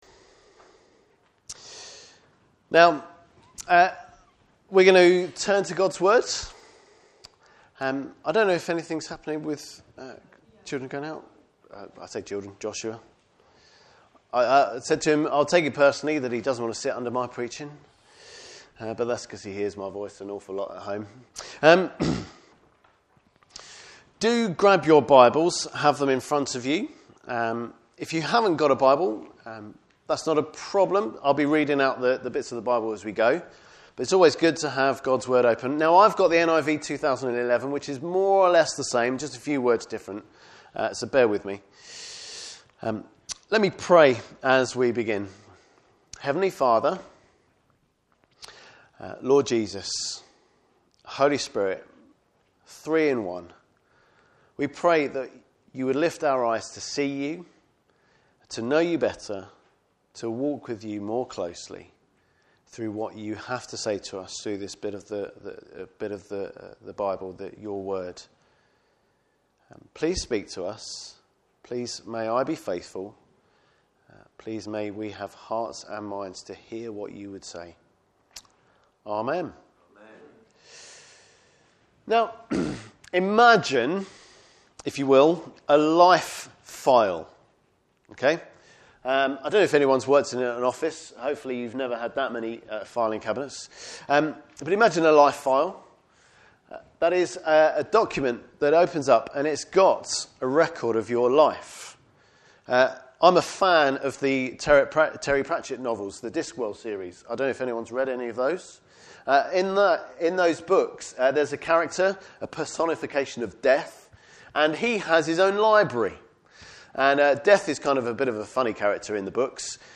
Service Type: Morning Service Bible Text: Psalm 51.